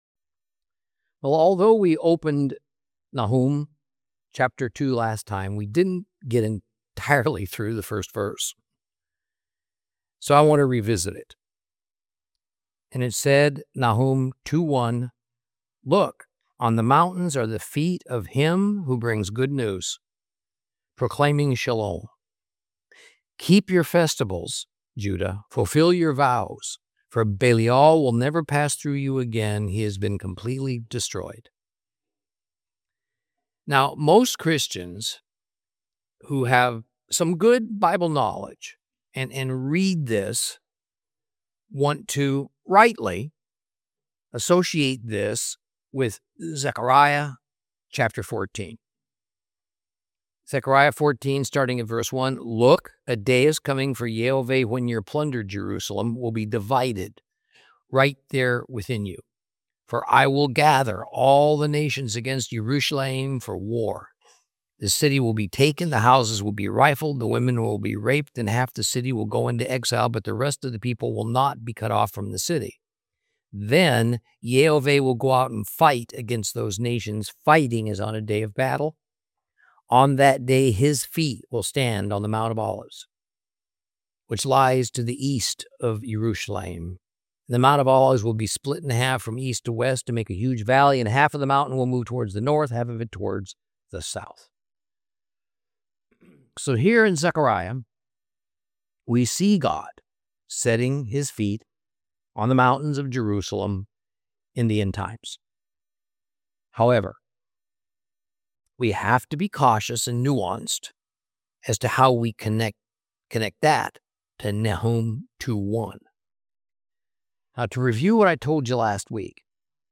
Teaching from the book of Nahum, Lesson 5 Chapter 2 continued.